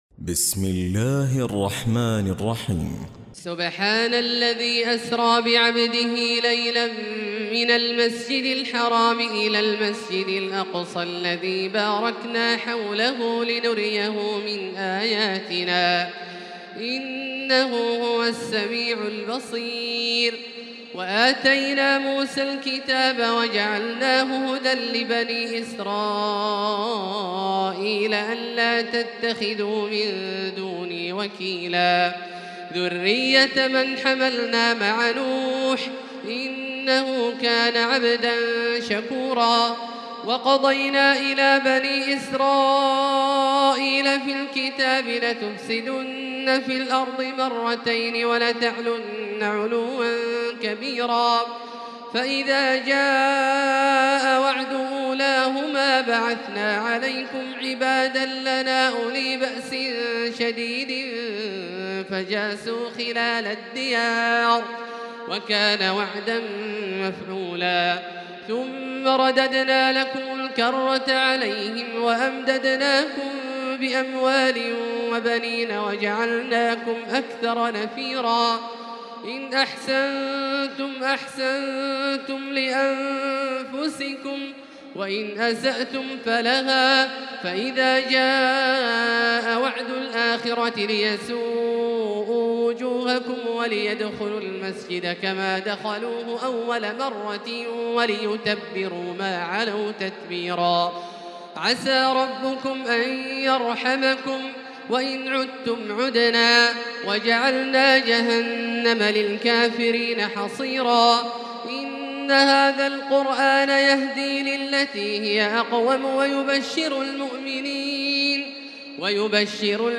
تراويح الليلة الرابعة عشر رمضان 1438هـ سورة الإسراء كاملة Taraweeh 14 st night Ramadan 1438H from Surah Al-Israa > تراويح الحرم المكي عام 1438 🕋 > التراويح - تلاوات الحرمين